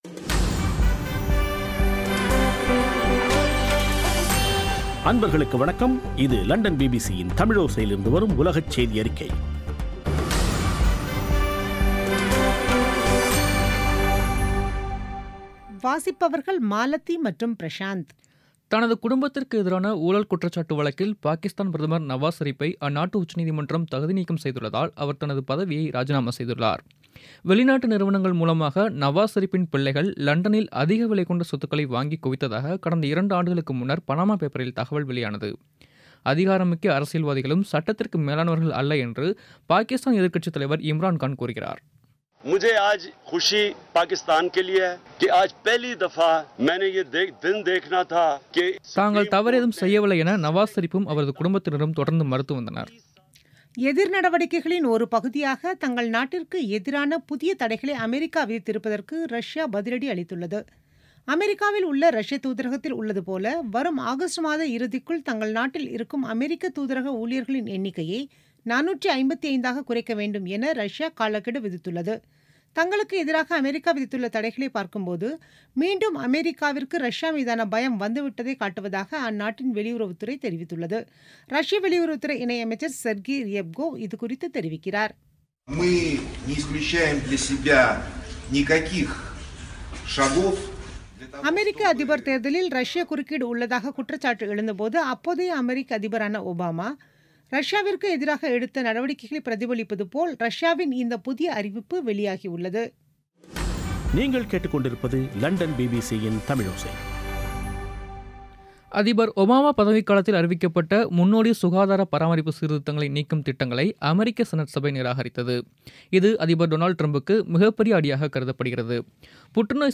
பிபிசி தமிழோசை செய்தியறிக்கை (28/07/2017